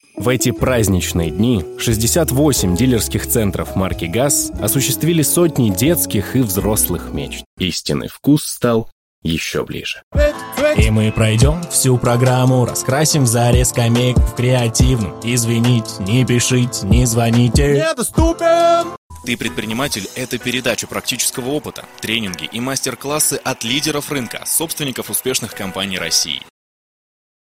AKG P120, Behringer U22, Reaper